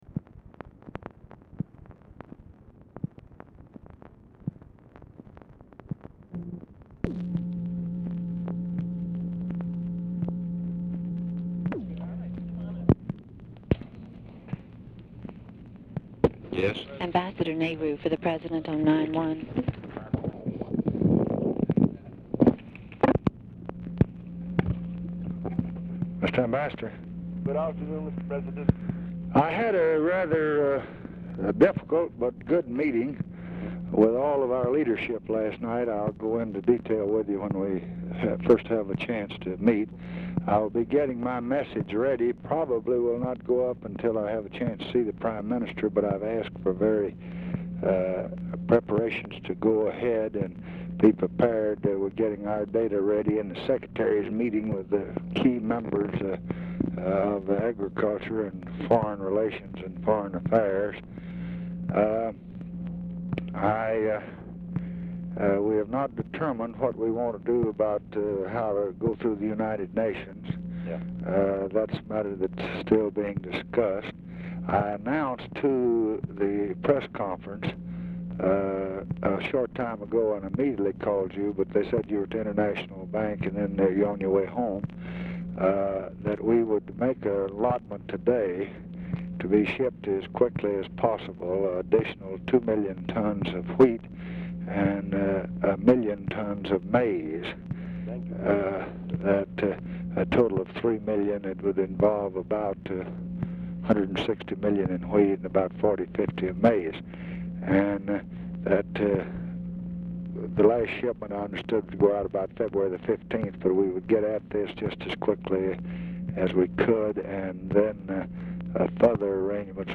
DAILY DIARY INDICATES LBJ IS MEETING WITH BILL MOYERS AT TIME OF CALL; MOYERS ANSWERS TELEPHONE; NEHRU ON HOLD 0:25
Format Dictation belt
Specific Item Type Telephone conversation